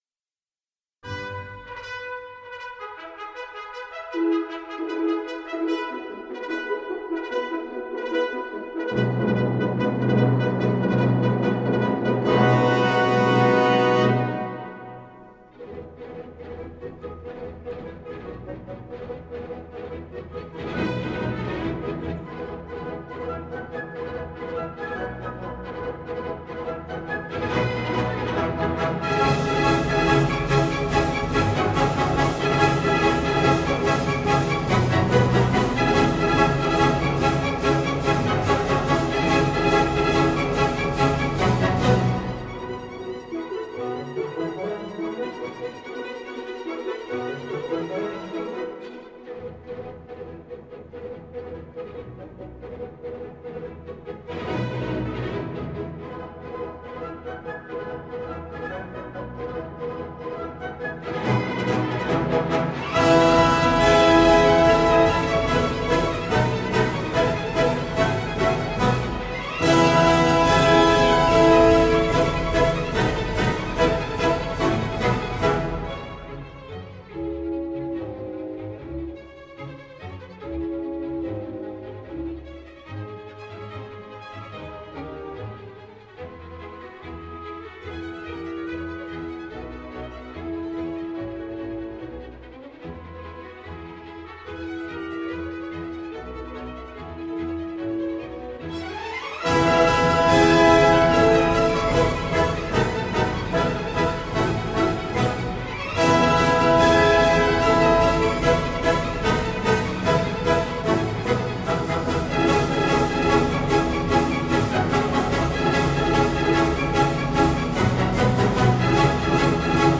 Klassische Musik